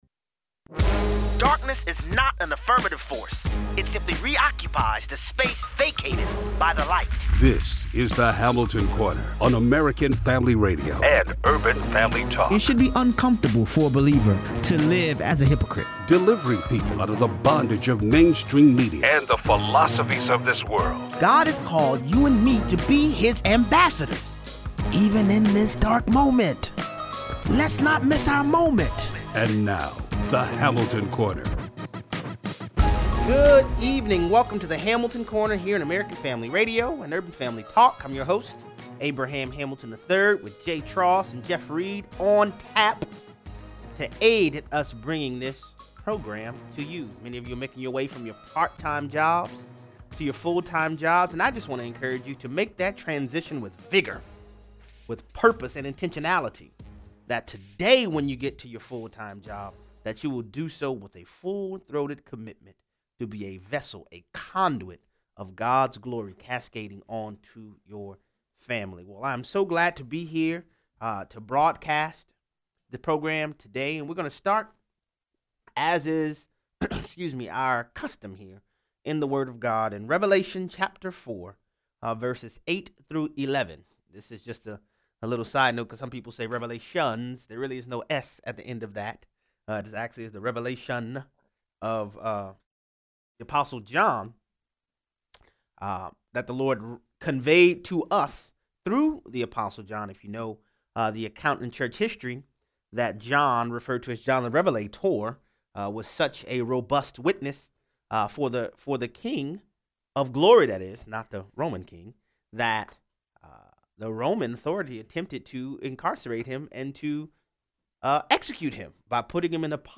While Mike Pompeo is on the plane returning with 3 US hostages from North Korea the New Times tries to slam him for “absence”... and then they crawfish. 0:43 - 0:60: UberAir, the next phase in transportation? Callers weigh in.